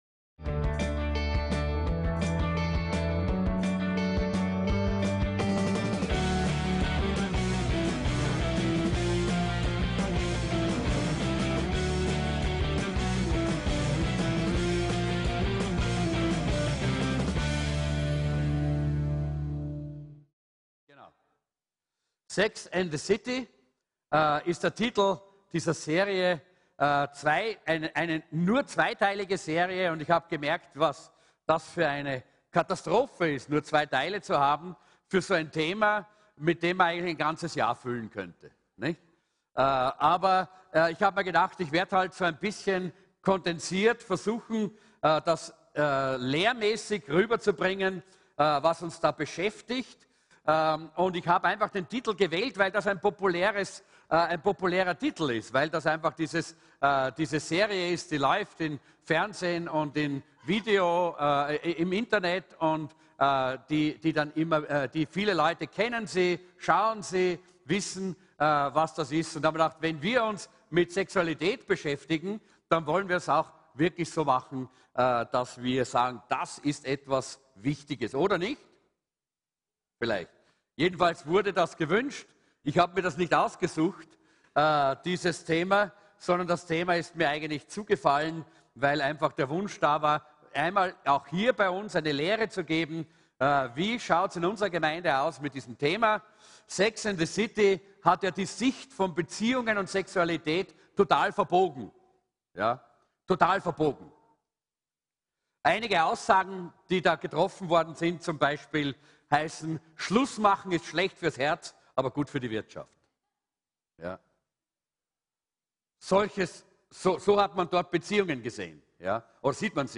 WAS SAGT DIE BIBEL ÜBER SEX - "SEX AND THE CITY" (1) ~ VCC JesusZentrum Gottesdienste (audio) Podcast